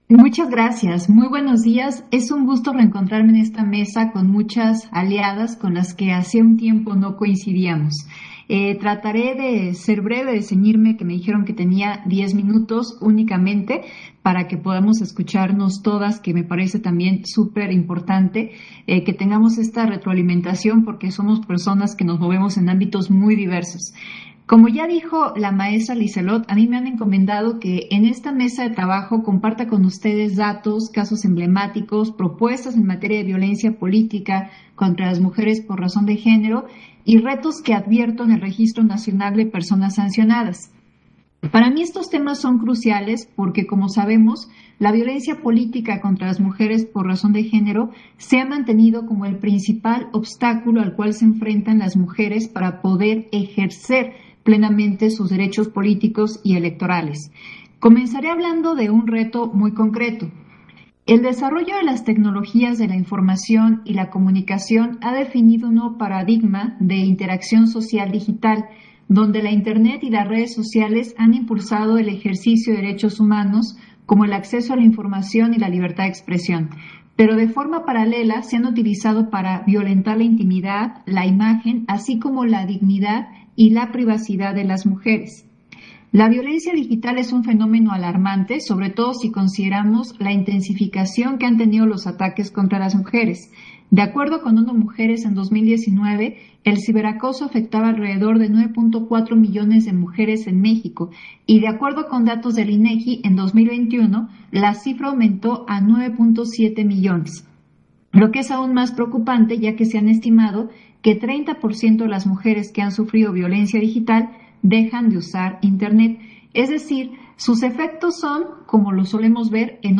Intervención de Dania Ravel, en el panel: Impacto de la violencia de género y la violencia política en llos derechos políticos-electorales de las mujeres. XXI Sesión Ordinaria de trabajo del Observatorio de Participación Política de las Mujeres